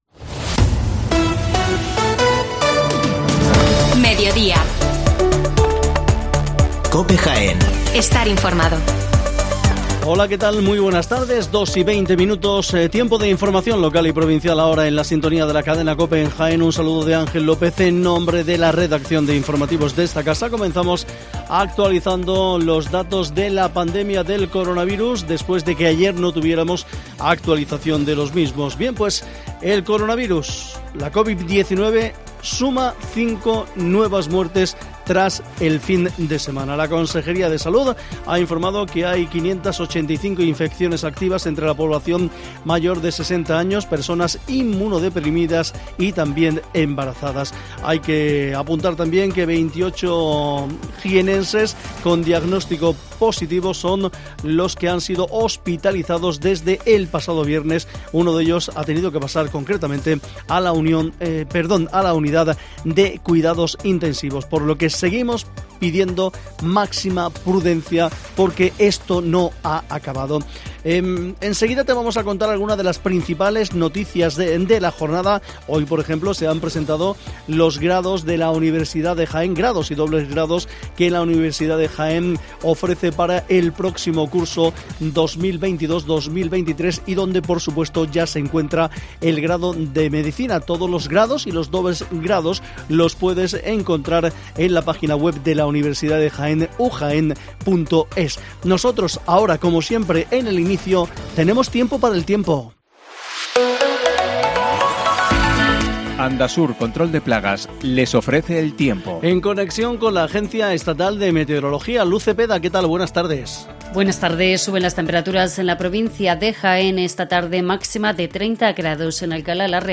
Las noticias locales en Mediodía COPE (17/05/22)